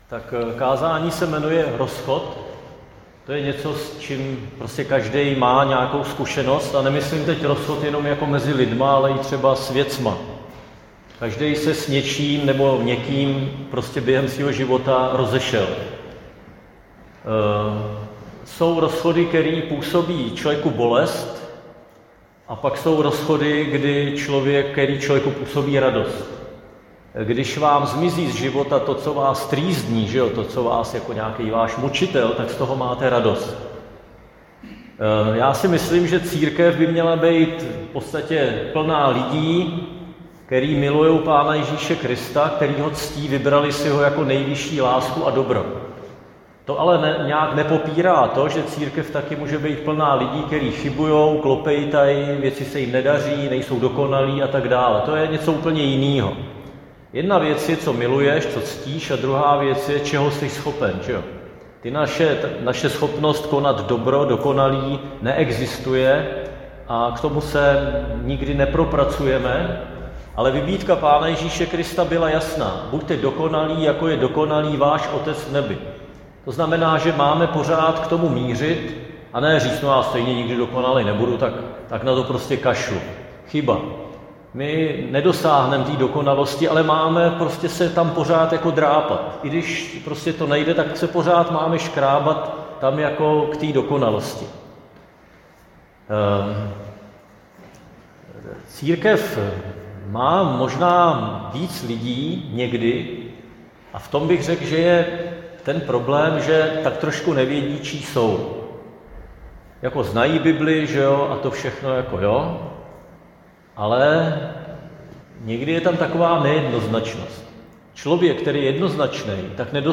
Křesťanské společenství Jičín - Kázání 18.5.2025